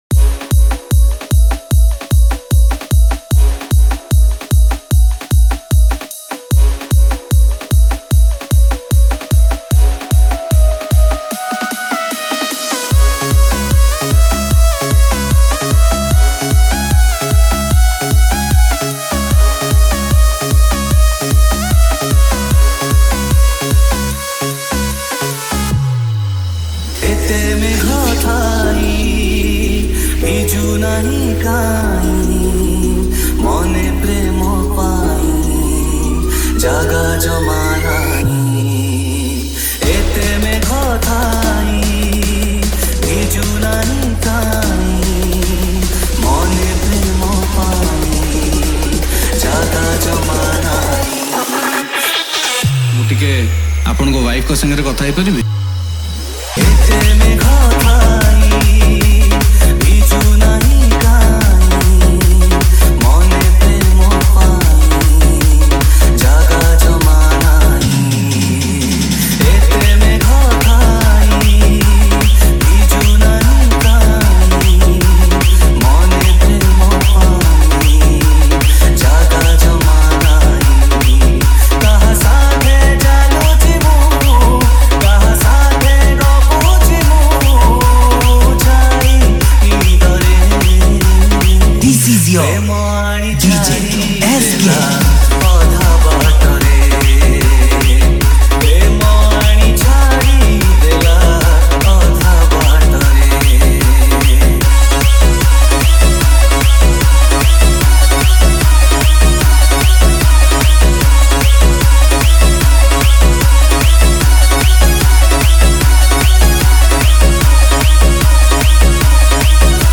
Category:  New Odia Dj Song 2025